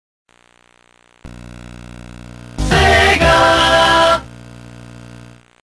Sega Genesis Startup Sound Effect Free Download